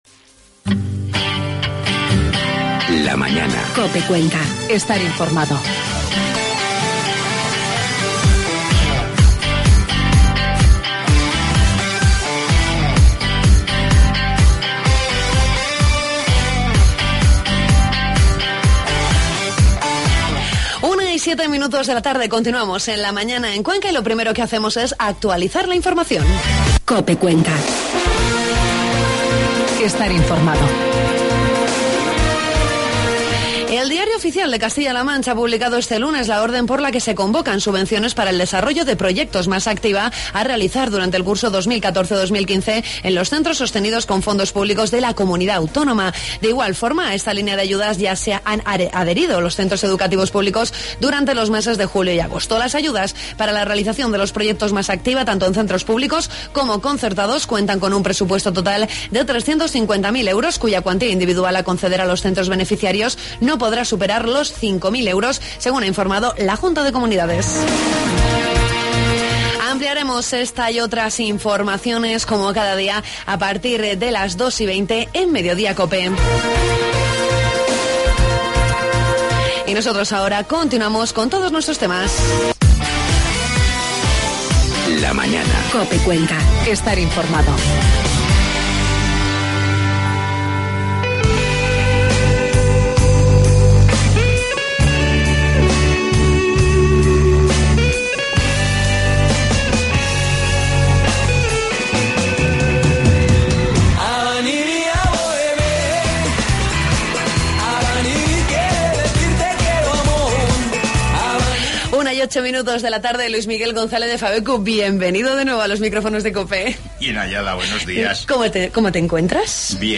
Entrevistamos al alcalde de Motilla del Palancar, Jesús Ángel Gómez, con el que conocemos el programa de fiestas de la localidad que se desarrollará hasta el 6 de septiembre.